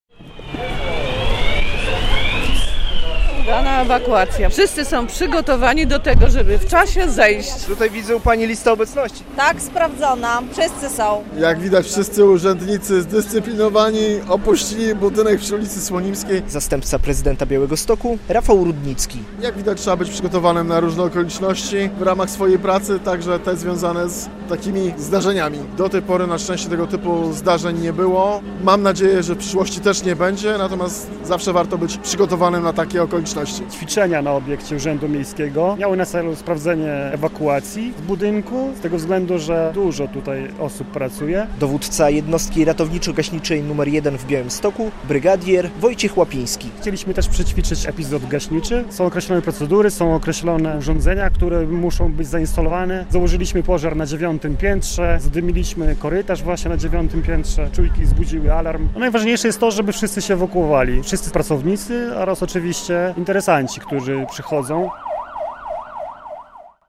Próbna ewakuacja w Urzędzie Miejskim w Białymstoku - relacja